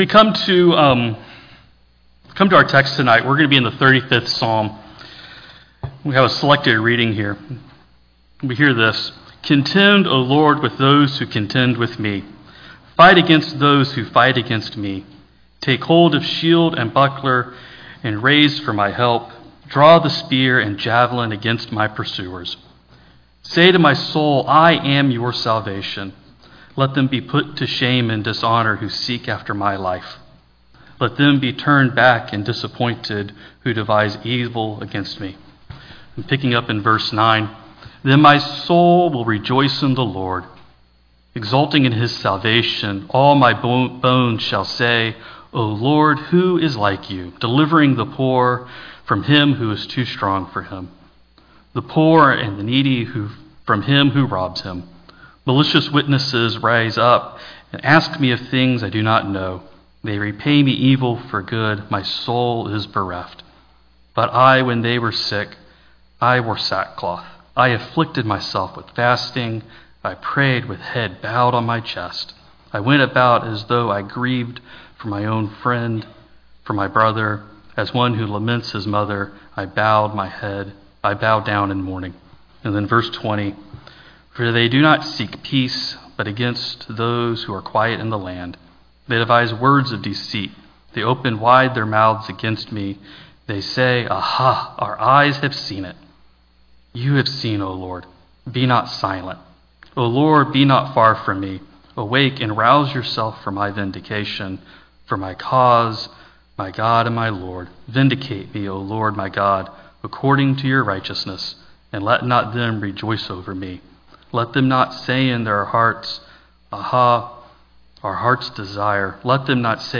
Text for Sermon: Psalm 35:1-4, 9-14, 20-28